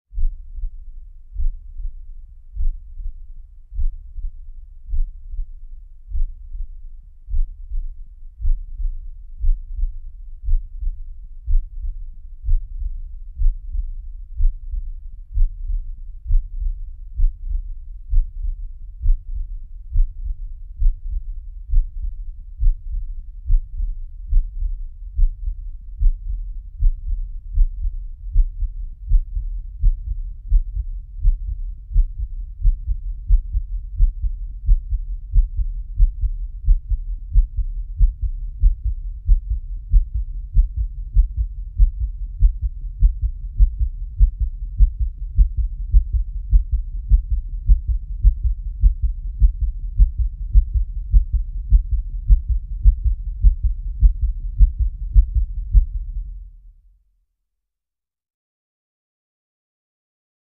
Heartbeat; Very Low Eerie Sub Frequency, Speeding Up.